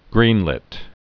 (grēnlĭt)